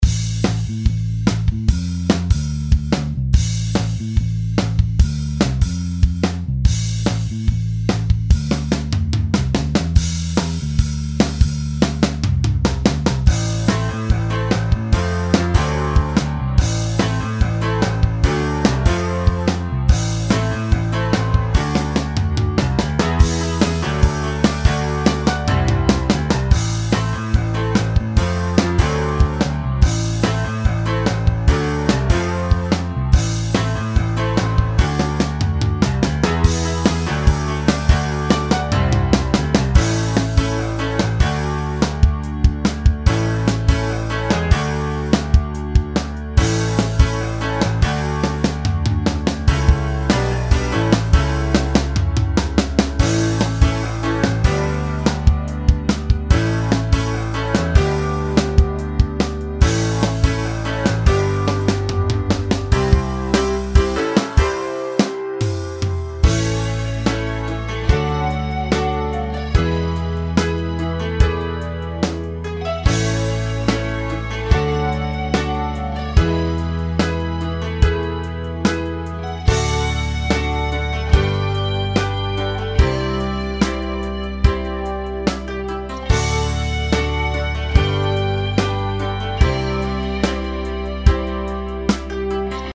Atlanthropia” is a rock opera that tells the tragic story of fictional people who might well have existed.